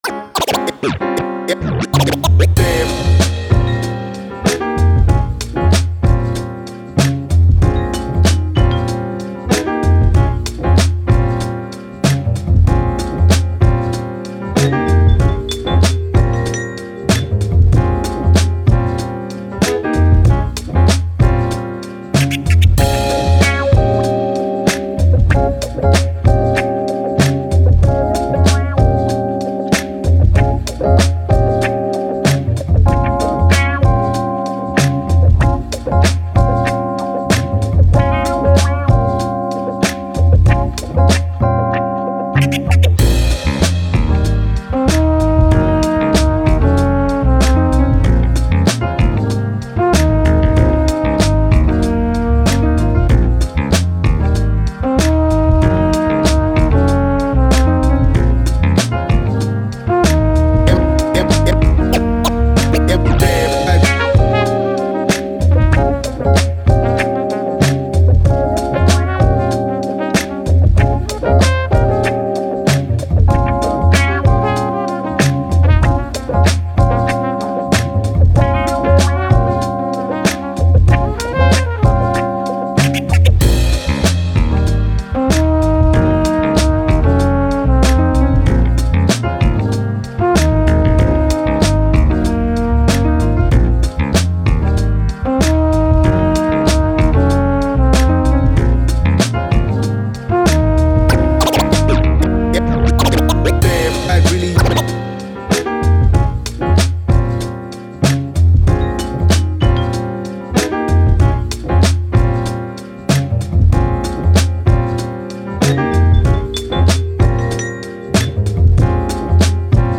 Hip Hop, Upbeat, Positive, Vibe, Vintage